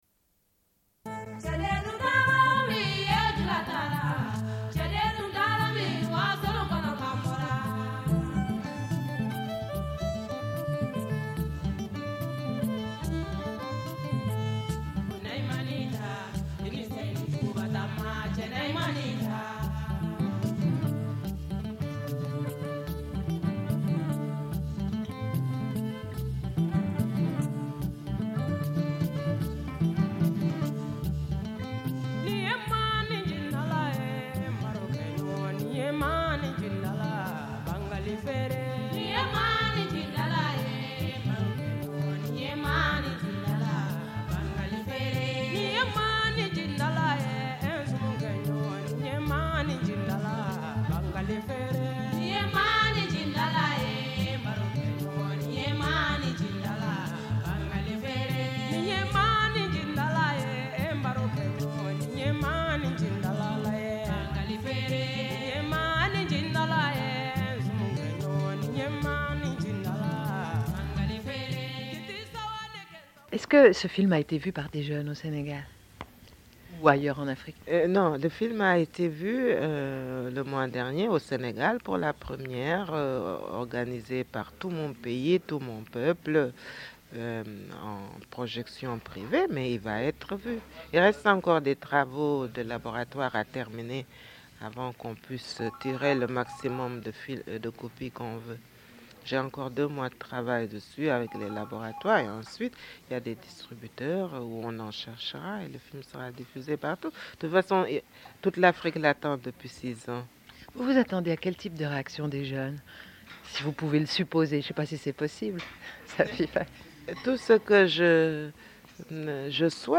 Diffusion d'un entretien.